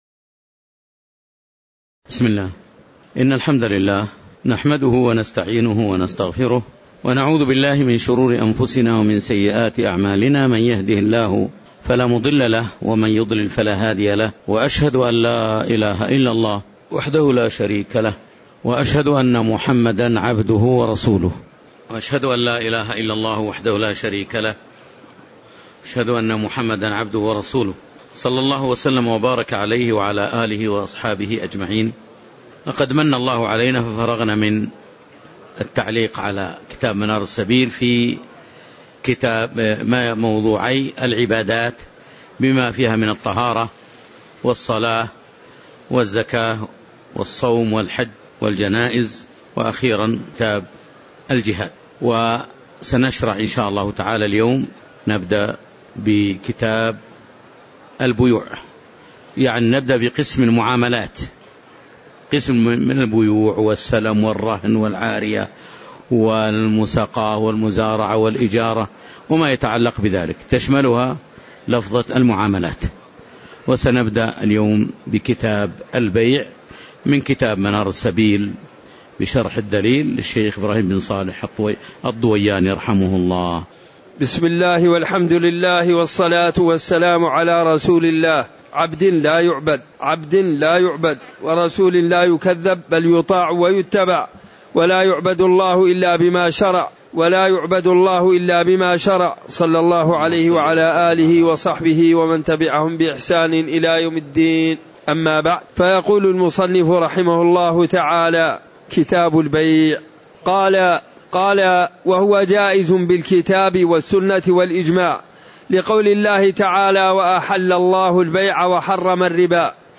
تاريخ النشر ١ محرم ١٤٤٠ هـ المكان: المسجد النبوي الشيخ